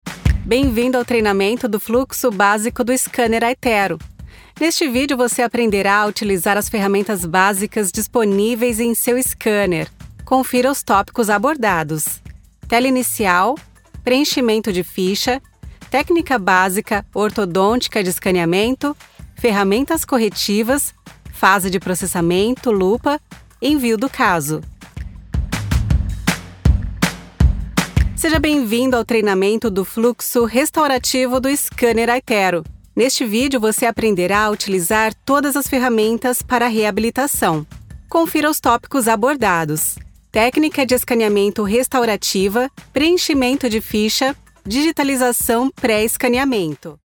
E-Learning
Sie hat einen authentischen und freundlichen Stil und ist sehr auf Exzellenz und die Zufriedenheit ihrer Kunden bedacht.
Ihre Stimme ist angenehm und vielseitig und kann Ihnen helfen, ein einzigartiges und fesselndes Hörerlebnis für Ihr Publikum zu schaffen.
Ich habe ein professionelles Heimstudio mit allem Drum und Dran.
Acoustic Cabin, Reaper, Sound Forge, SSL 2 Interface, Mic ARC STD-3, Sony MDR-7506 Headphones